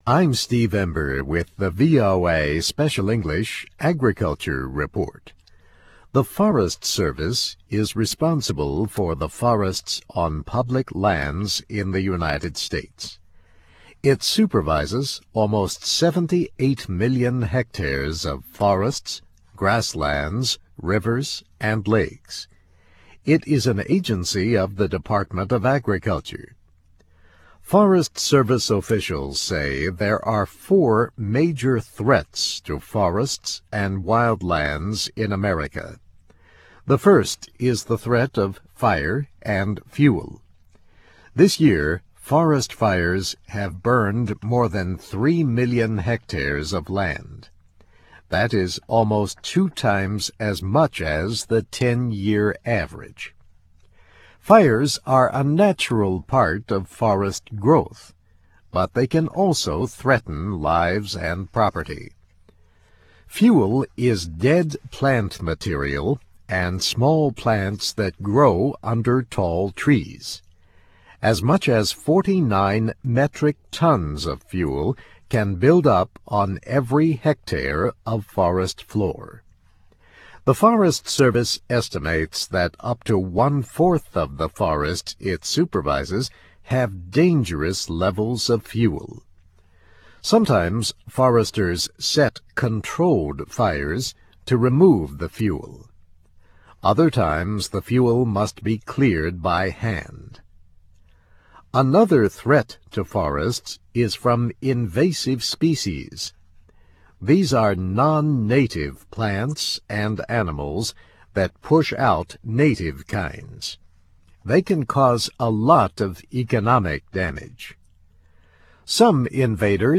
VOA News - audio activity